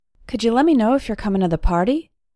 #2 – Native speakers talk fast and “connect” words together
sounds like this: Couldja lemmenoif yercomin atheparty?